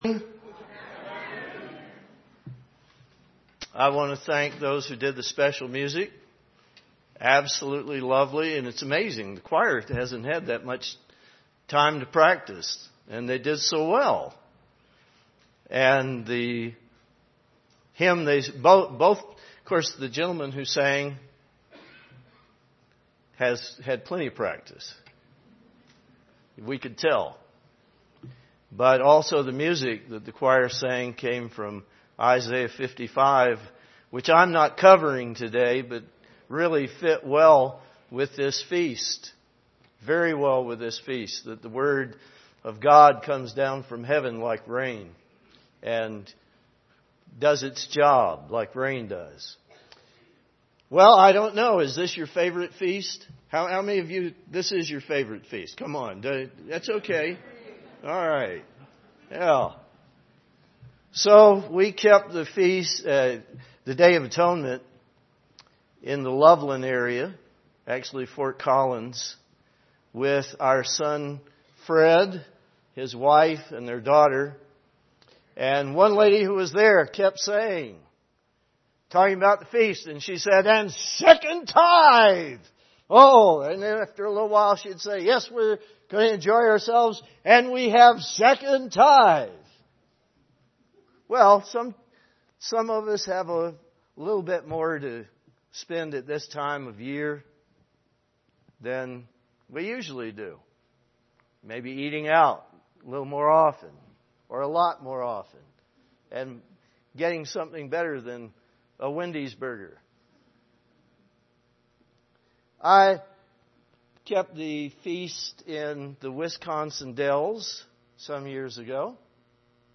This sermon was given at the Steamboat Springs, Colorado 2015 Feast site.